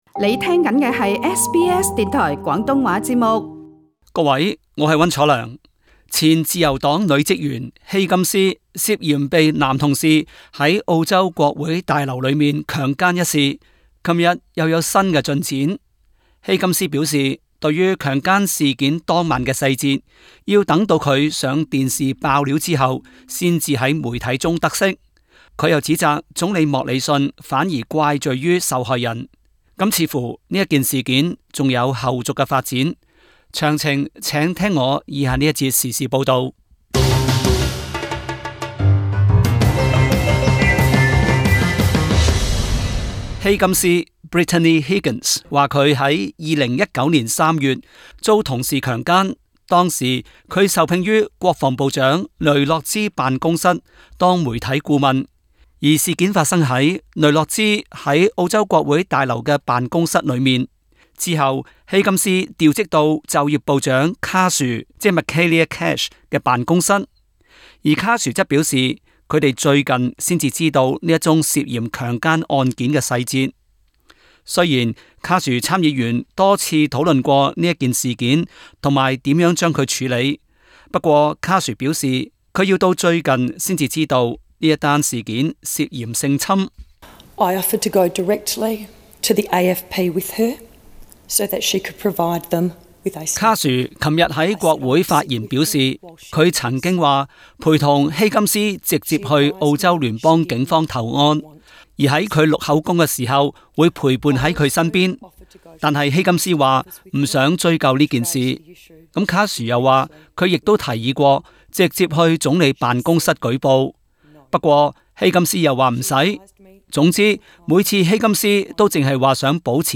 Source: AAP SBS廣東話節目 View Podcast Series Follow and Subscribe Apple Podcasts YouTube Spotify Download (9.38MB) Download the SBS Audio app Available on iOS and Android 前自由黨職員希金斯兩年前涉嫌被男同事於澳洲國會大樓裏強姦一事有新進展。